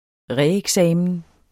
Udtale [ ˈʁε- ]